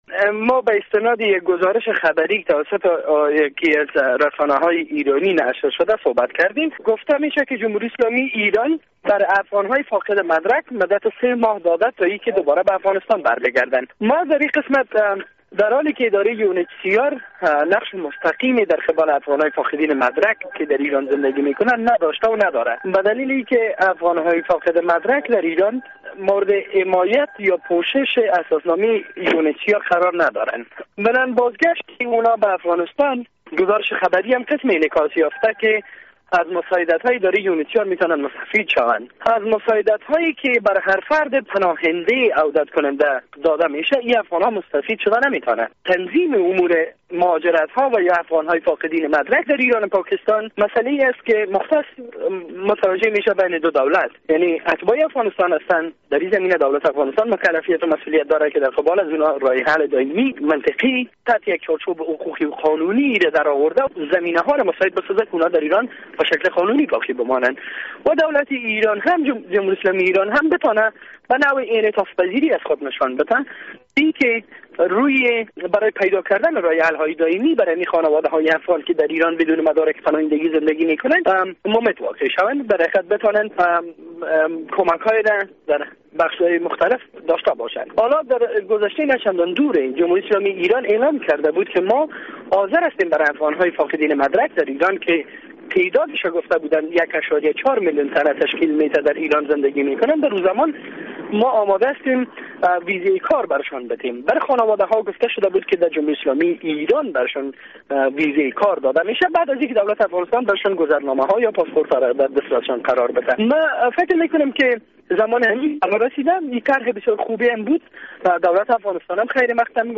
مصاحبه در مورد اخراج 900 هزار مهاجر افغان از ایران